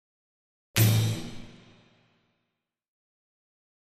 Drum Hit Symphonic Drum And Thin Cymbal Hit